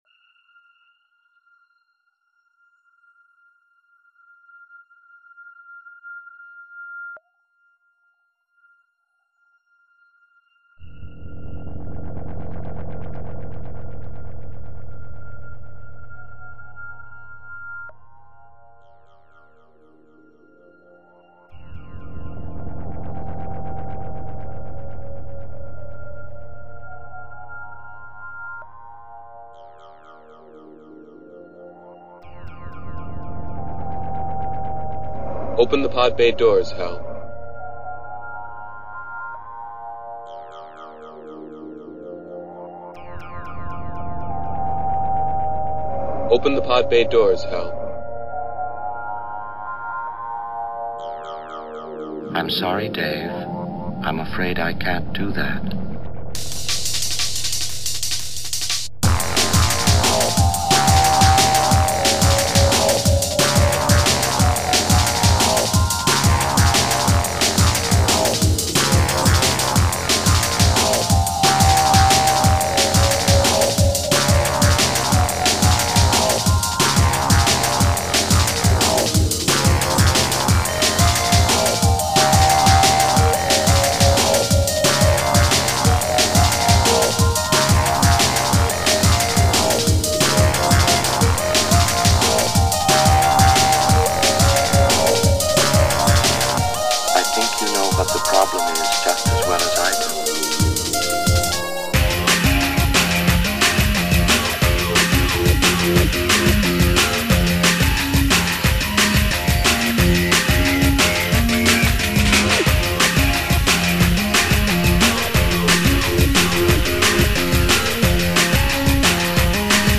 Drum and bass
and lots of drums.